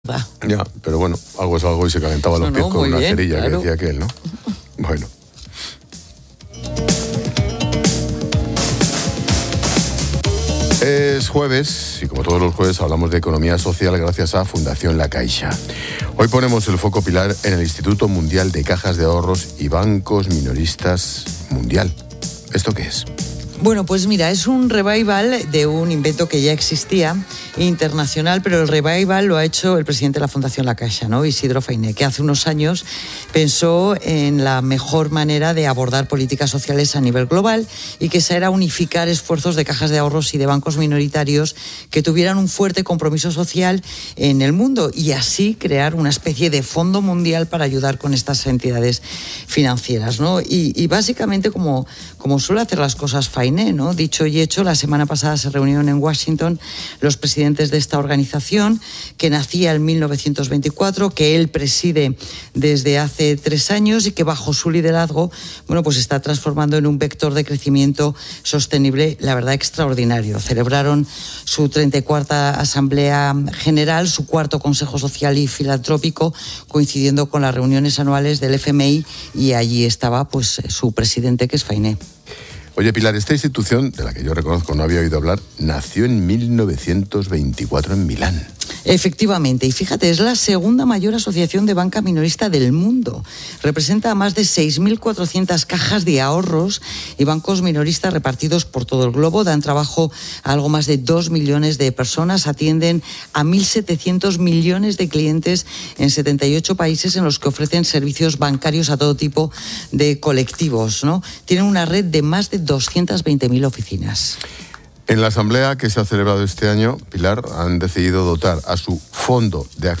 Ángel Expósito, la experta económica y directora de Mediodía COPE, Pilar García de la Granja, hablan sobre el Instituto Mundial de Cajas de Ahorros y Bancos Minoristas